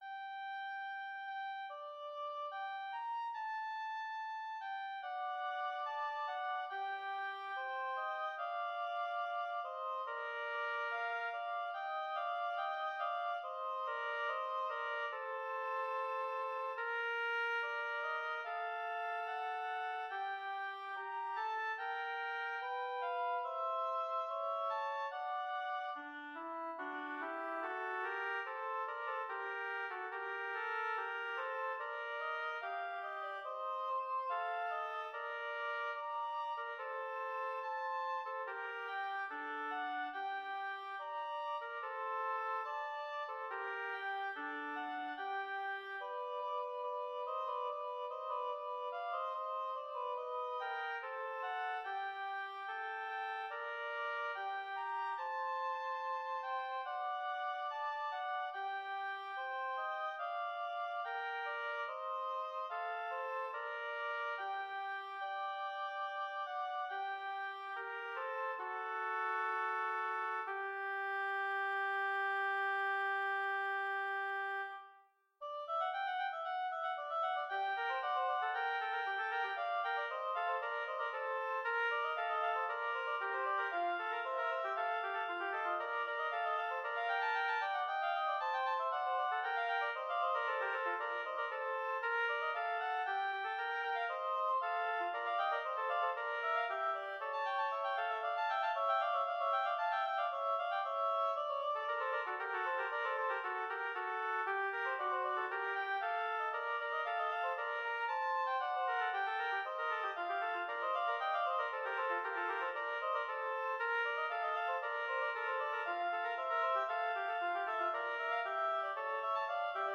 Intermediate oboe duet
Instrumentation: oboe duet